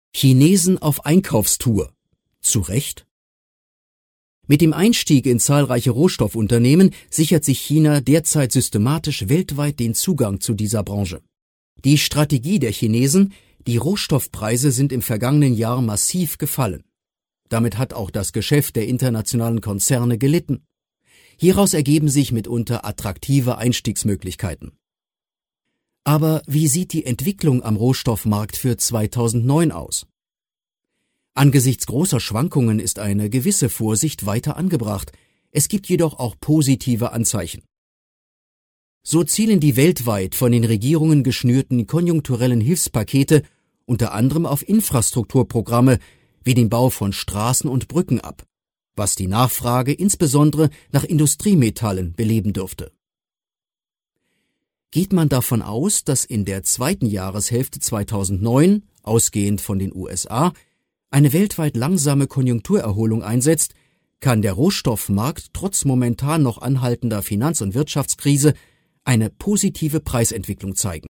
deutscher Profi-Sprecher. Breites Spektrum von sachlich bis ausgeflippt (Trickstimme).
Sprechprobe: Sonstiges (Muttersprache):